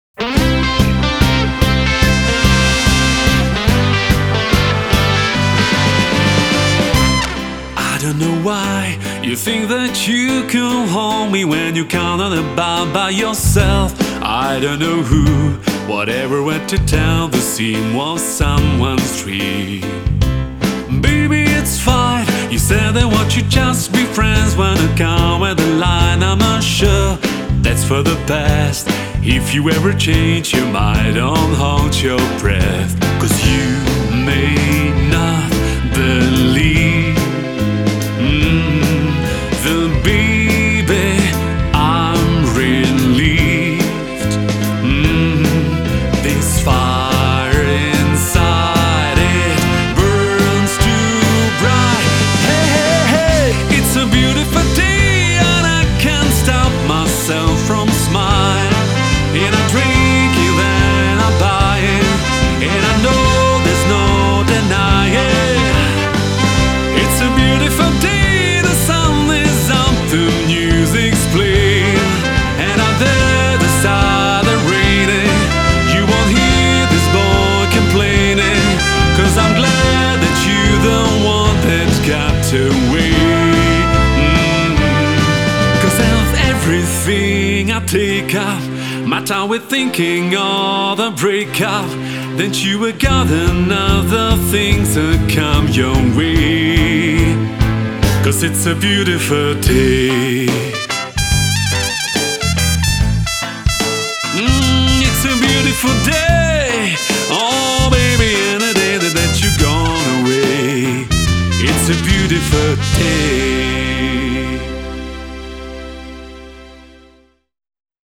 2017 – výběr z populární hudby (zkrácené verze)